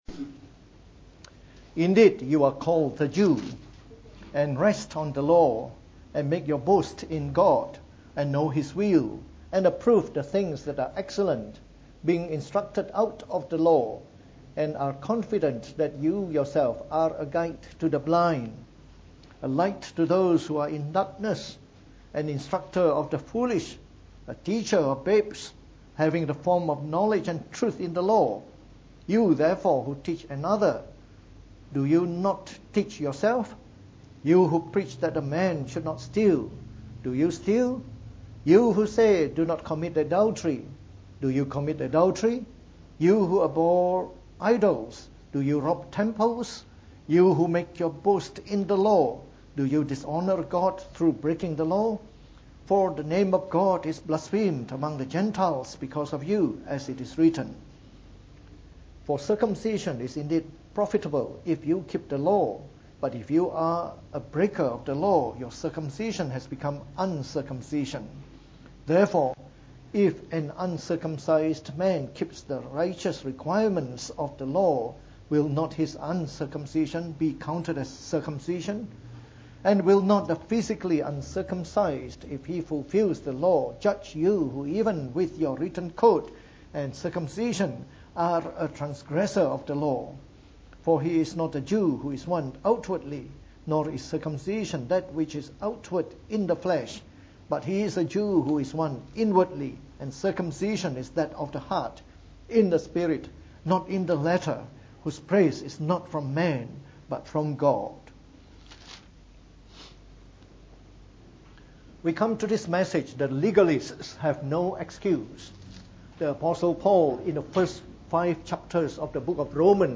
From our series on the Book of Romans delivered in the Morning Service.